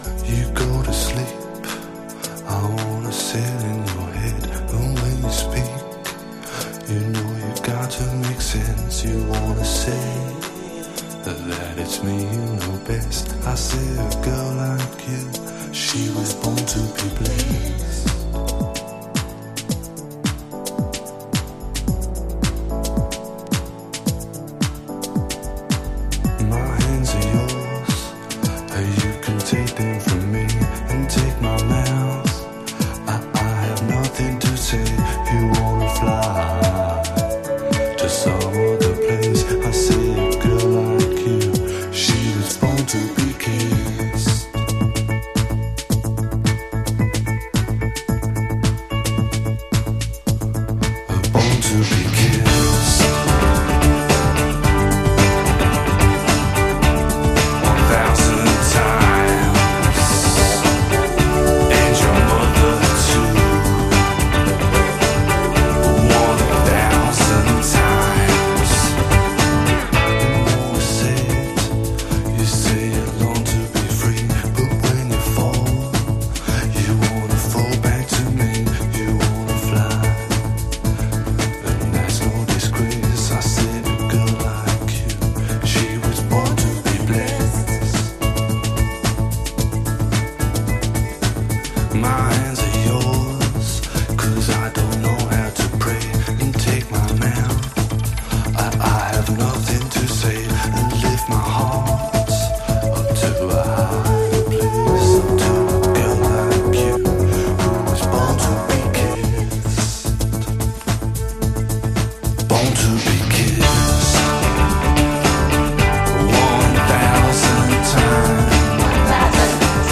マッドチェスター/インディーダンス好きも必聴！